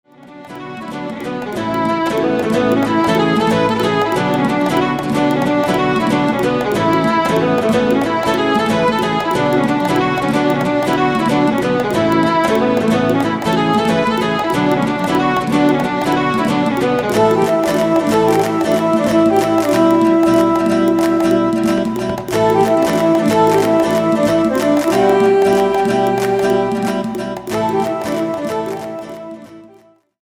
(音量注意)
かなりケルト風、かつ酒場風にしました。
楽器もほぼ全部民族楽器でガチムチです。